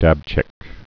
(dăbchĭk)